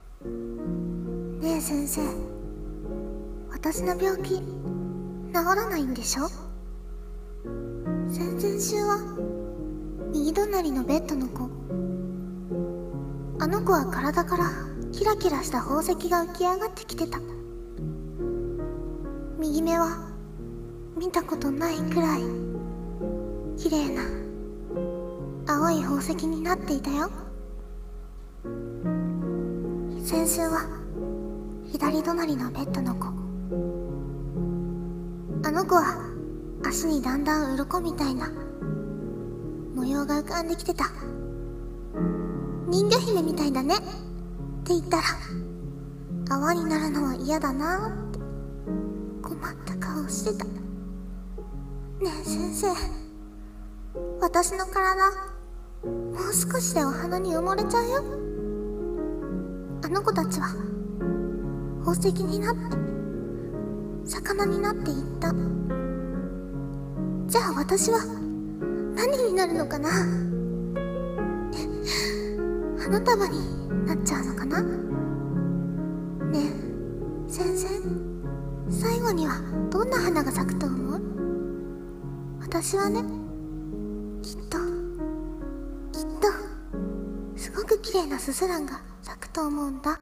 【一人声劇】スズランの花束